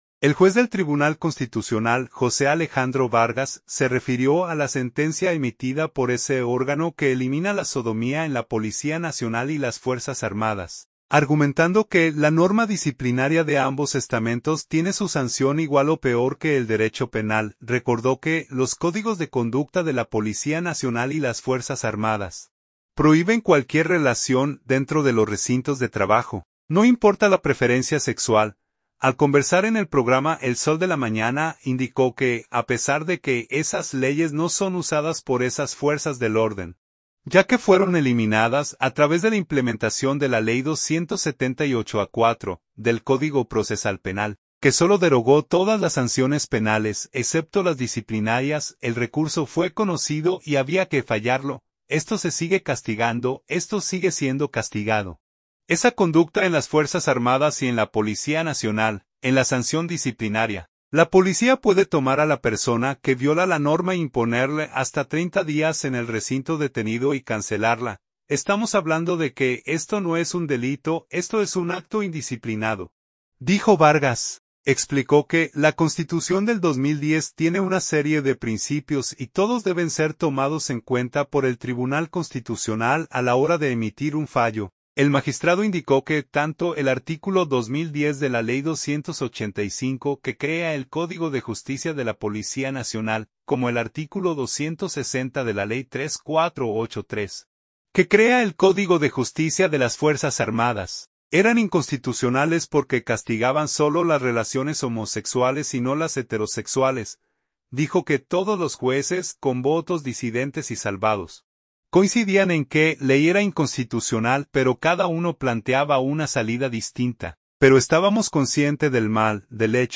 Al conversar en el programa “El sol de la mañana”, indicó que, a pesar de que esas leyes no son usadas por esas fuerzas del orden, ya que fueron eliminadas a través de la implementación de la Ley 278-04, del Código Procesal Penal, que solo derogó todas las sanciones penales, excepto las disciplinarias, el recurso fue conocido y había que fallarlo.